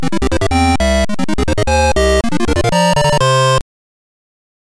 square waves